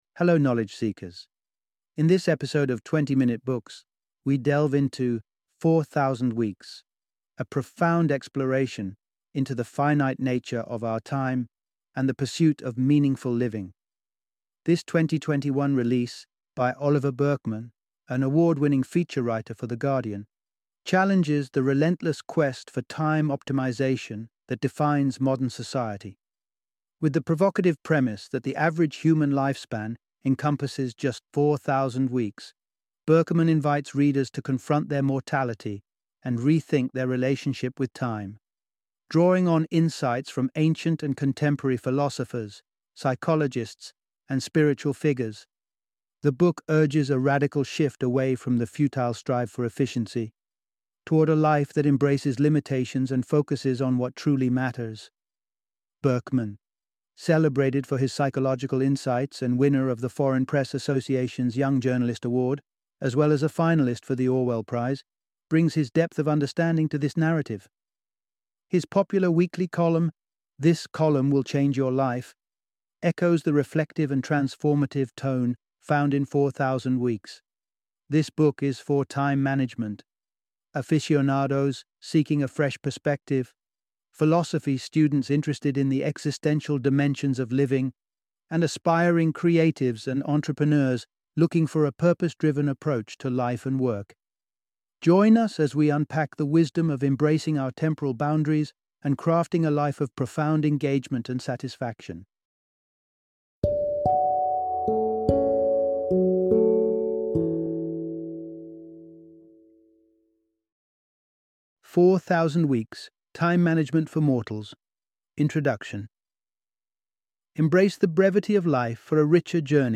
Four Thousand Weeks - Audiobook Summary